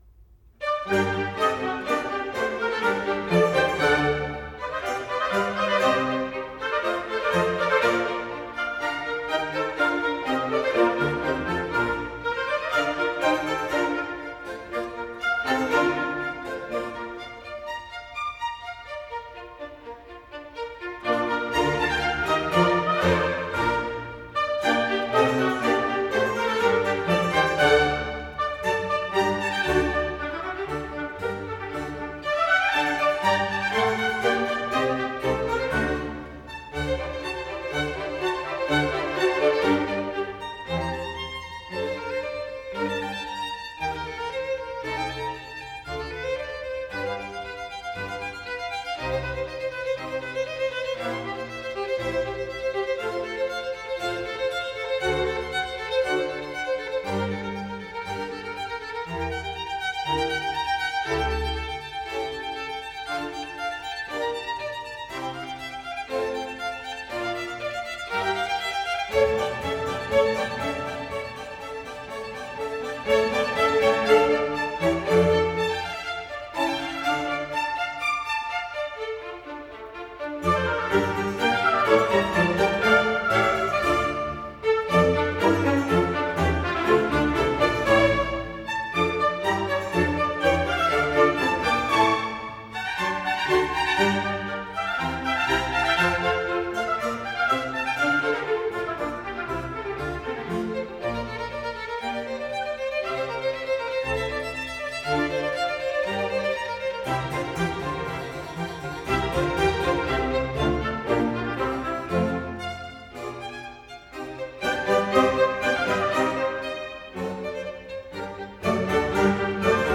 G minor - Allegro